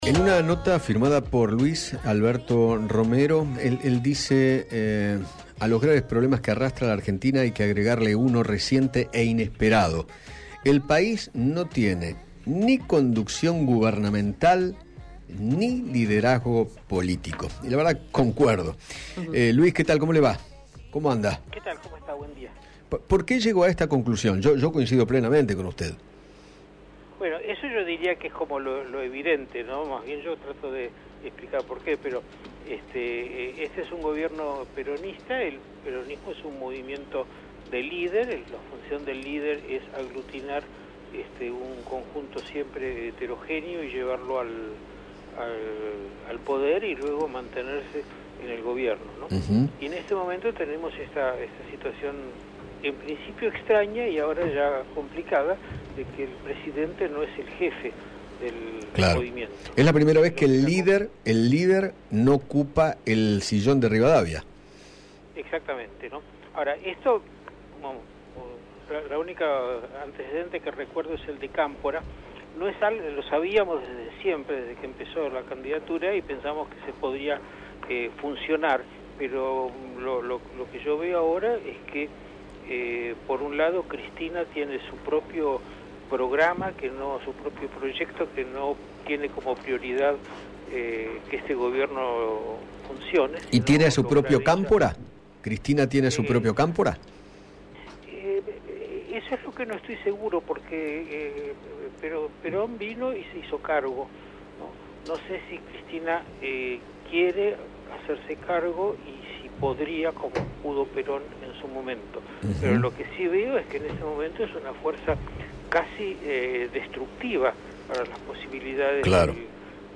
El historiador Luis Alberto Romero habló con Eduardo Feinmann sobre lo perjudicial que es la presencia de Cristina Kirchner en el Gobierno y aseguró que “el principal enemigo es ella”.